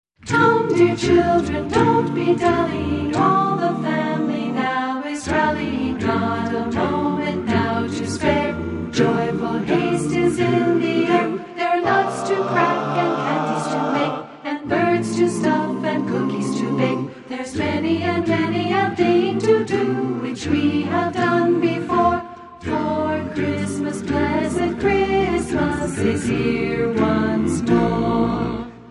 carols in their original form, a cappella.